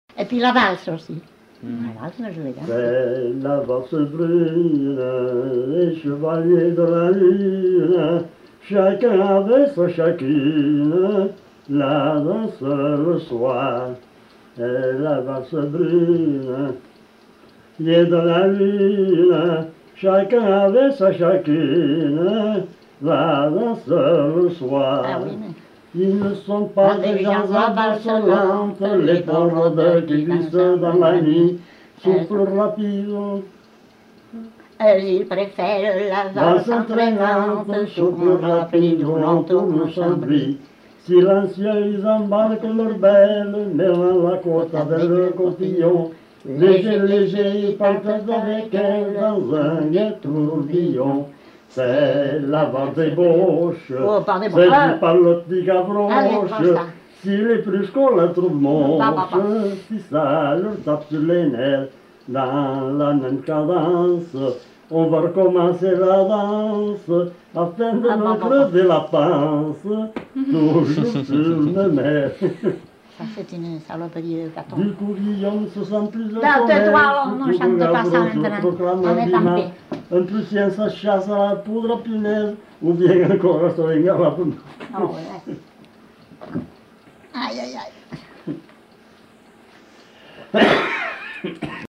Aire culturelle : Couserans
Lieu : Pause-de-Saut (lieu-dit)
Genre : chant
Effectif : 2
Type de voix : voix d'homme ; voix de femme
Production du son : chanté
Danse : valse